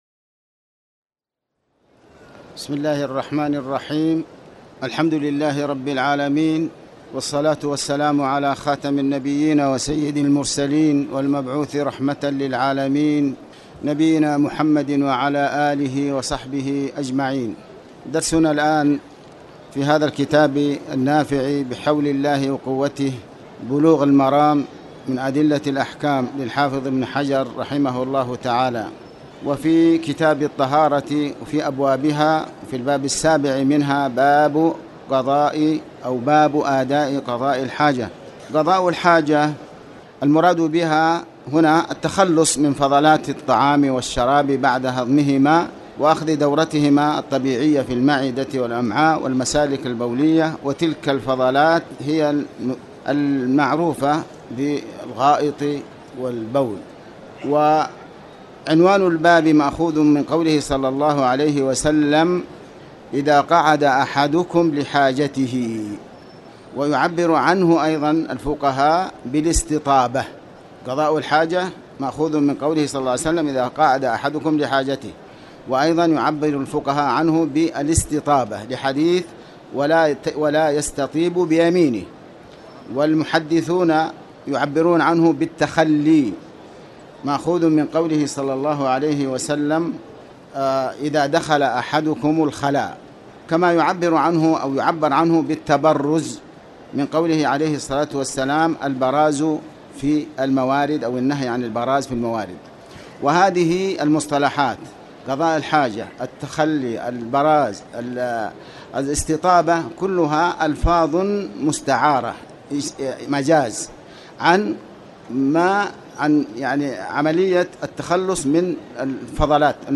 تاريخ النشر ١٥ شعبان ١٤٣٨ هـ المكان: المسجد الحرام الشيخ